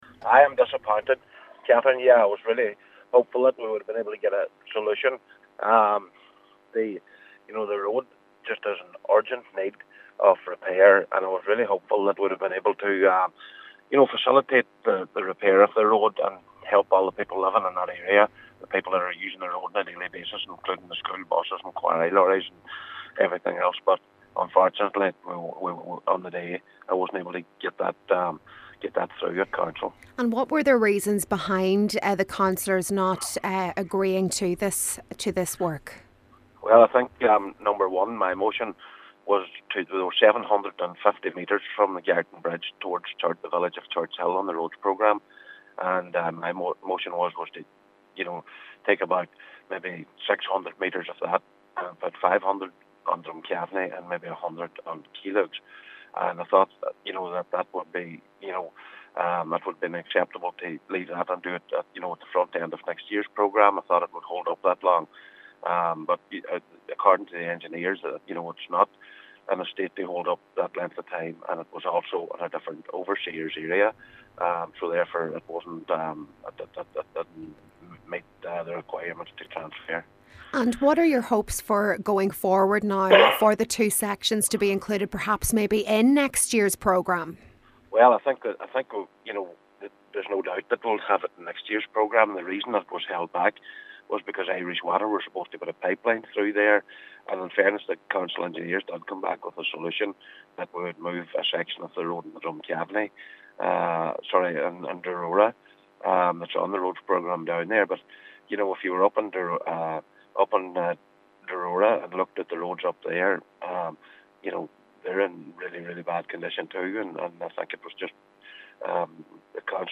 Cllr Michael McBride says it’s disappointing but is confident that the sections will be included in the programme in the future: